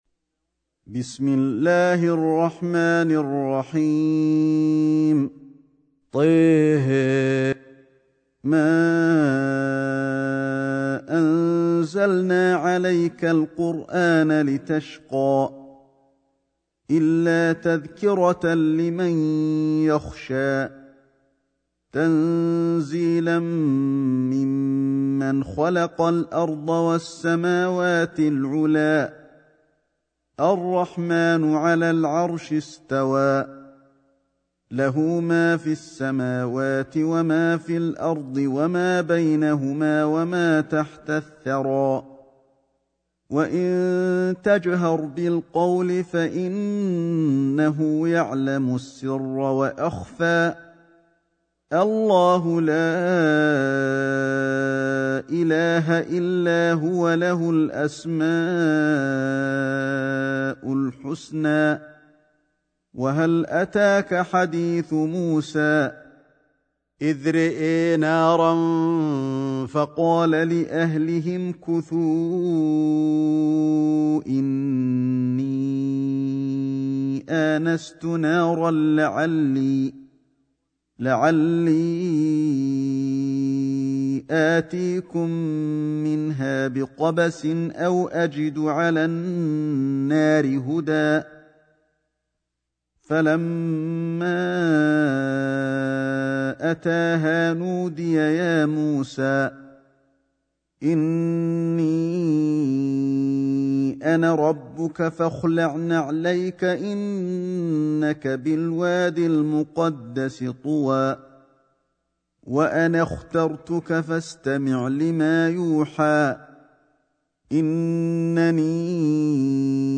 سورة طه > مصحف الشيخ علي الحذيفي ( رواية شعبة عن عاصم ) > المصحف - تلاوات الحرمين